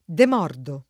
demordere [dem0rdere] v.; demordo [